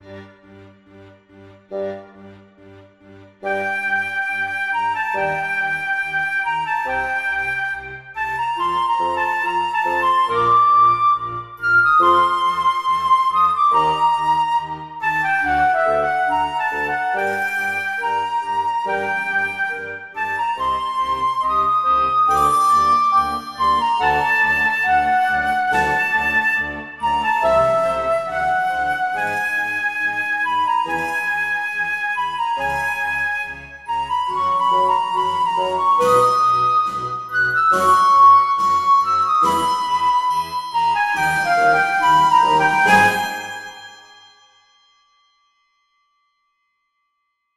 Seven varations for orchestra